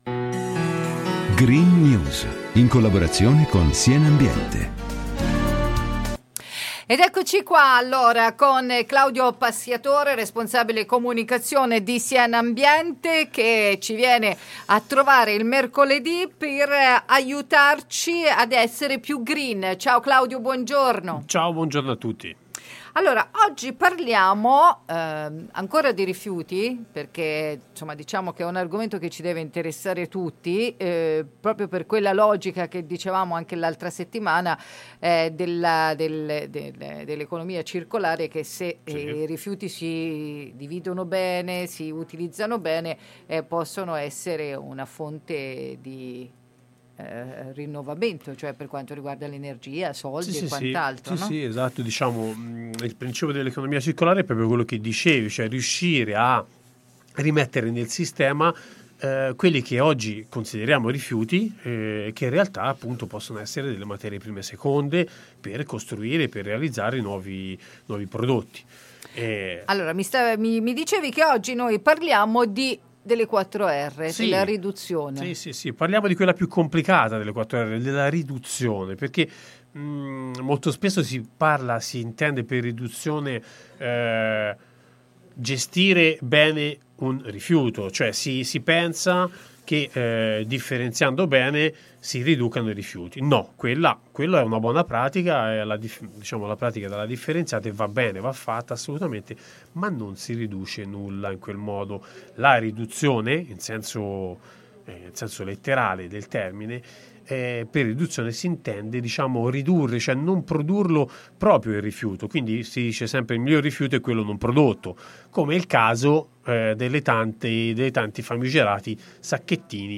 “Green news” – Sacchetti biodegradabili in aiuto dell’ambiente, ma serve saper gestire bene un rifiuto *intervista*